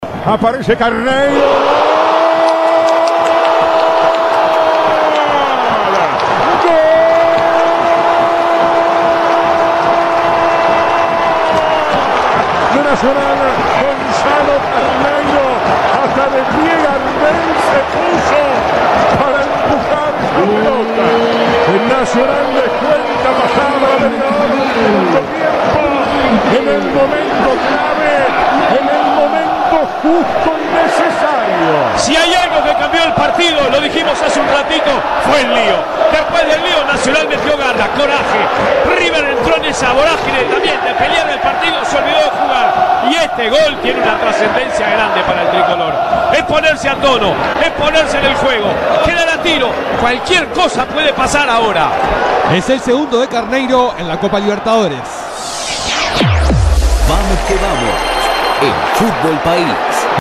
en la voz del equipo de Vamos que Vamos